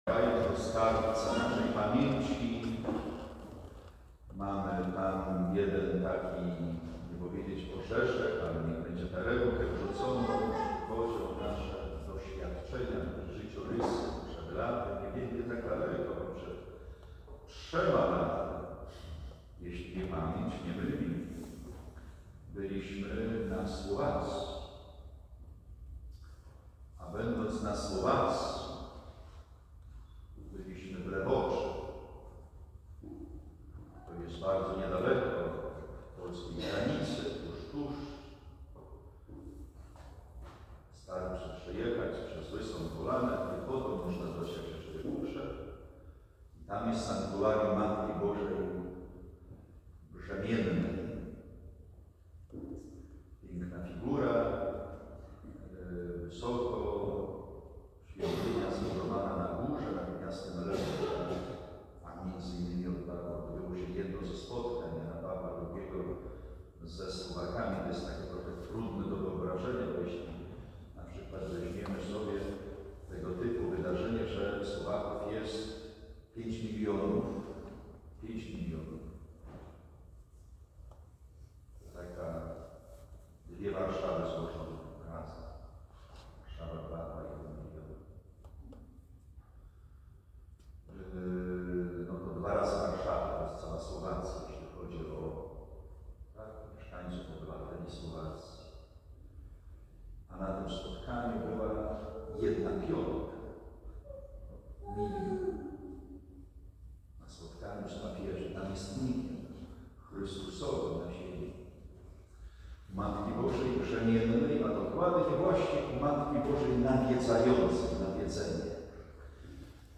homilia